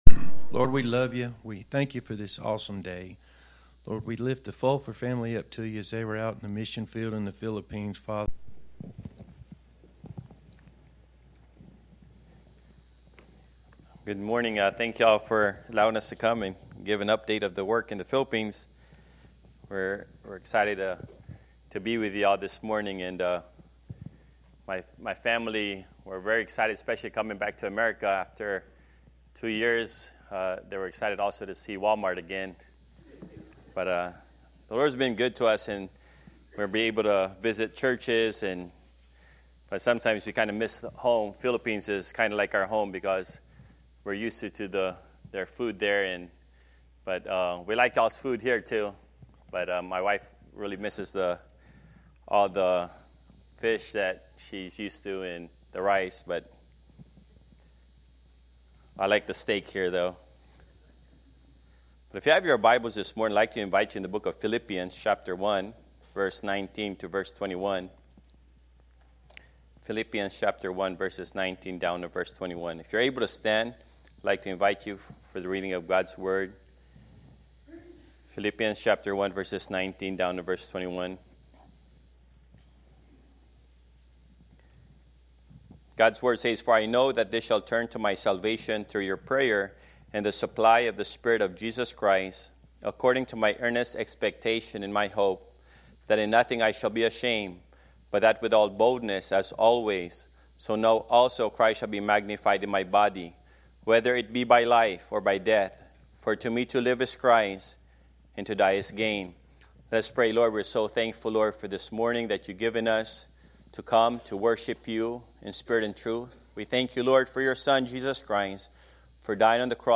Sermons by Mt. Olivet Baptist Church Stanley, LA